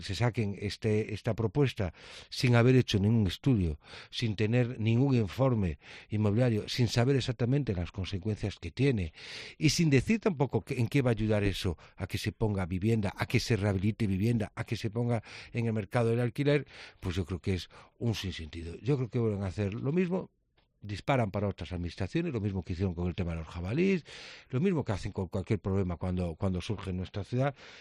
En COPE Coruña, el delegado territorial de A Coruña ha lamentado que “se saquen esta propuesta sin haber hecho ningún estudio, sin tener ningún informe inmobiliario, sin saber exactamente las consecuencias que tiene y sin decir tampoco en qué va a ayudar eso a que se ponga vivienda, se rehabilite y se ponga en el mercado del alquiler”.